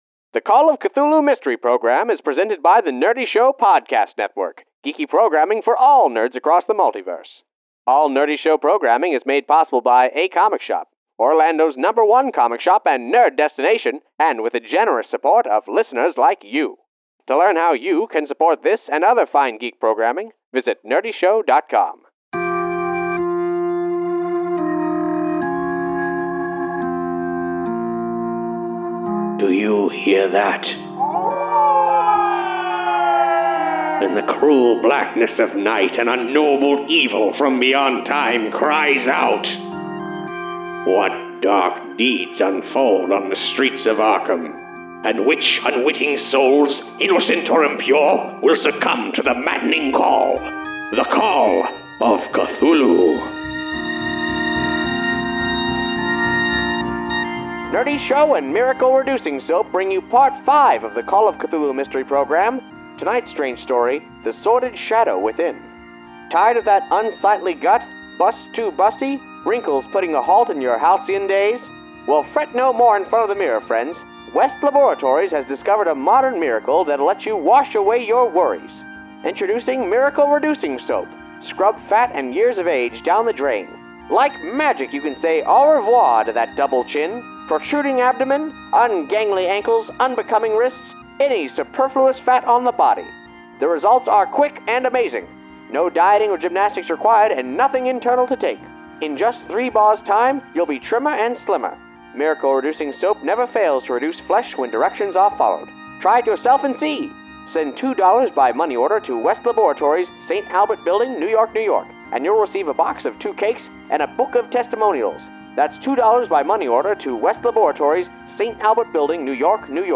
The Call of Cthulhu Mystery Program is live tabletop roleplaying turned into a 1930s radio serial.